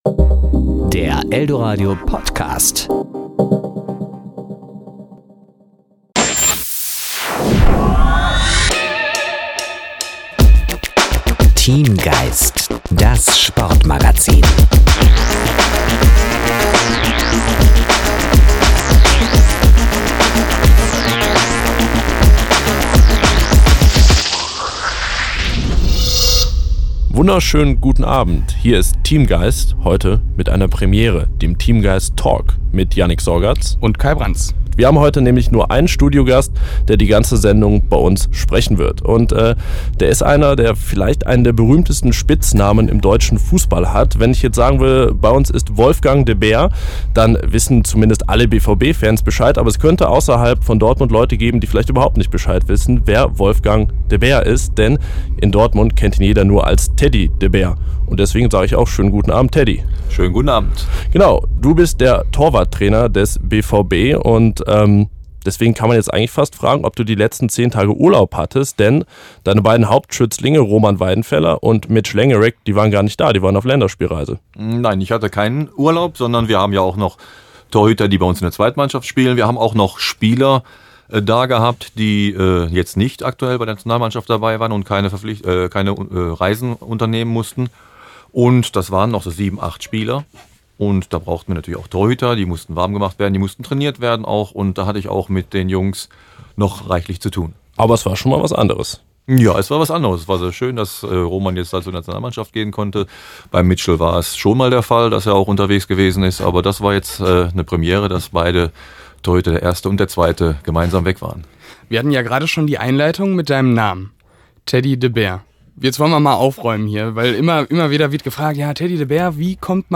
Und natürlich klären sie im eldoradio*-Studio, wie aus Wolfgang "Teddy" wurde.